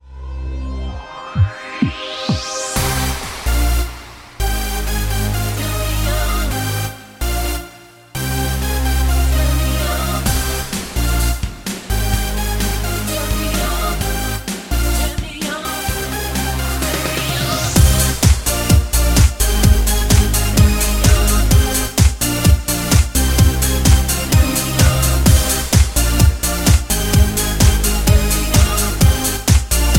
Backing track files: 2010s (1044)
Buy With Backing Vocals.